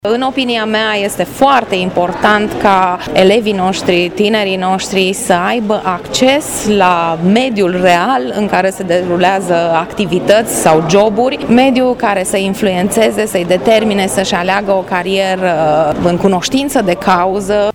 Inspectoratul Şcolar Judeţean a dat girul acestui proiect, după cum ne-a asigurat Ariana Bucur, Inspector Şcolar General